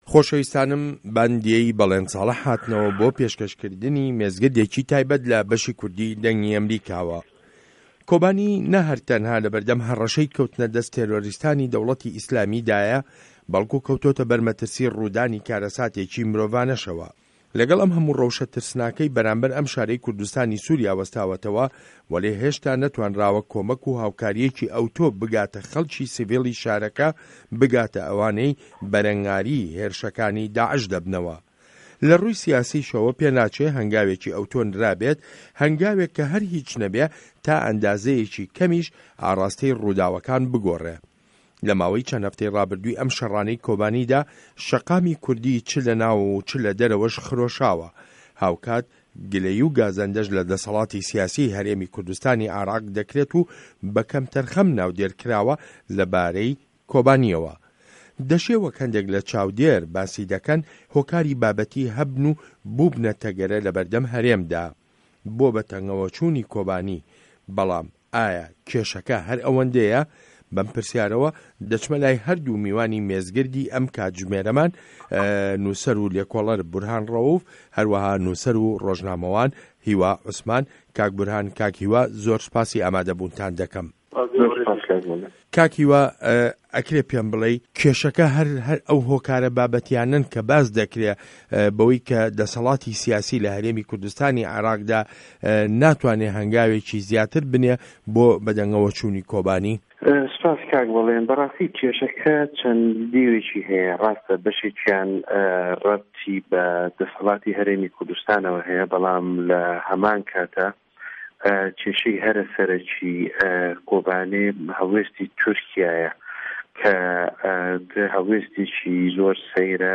مێزگرد: به‌ هاناوه‌ چوونی کۆبانی